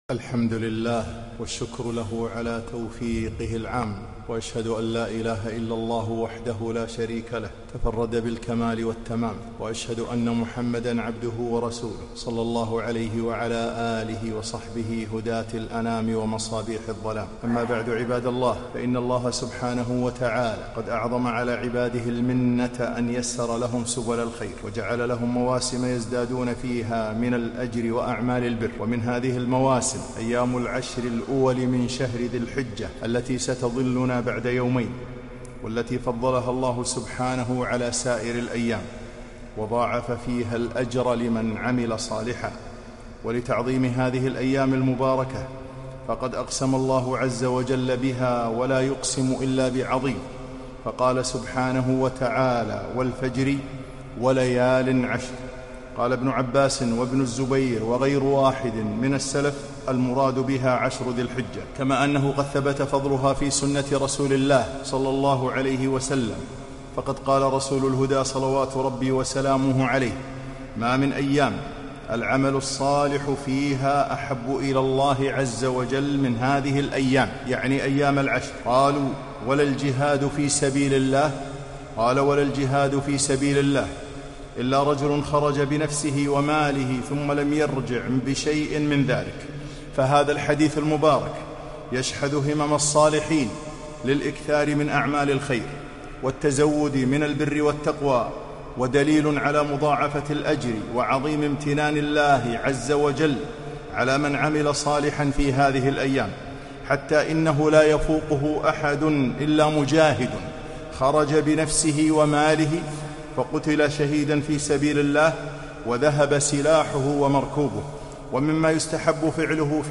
خطبة - فضل عشر ذي الحجة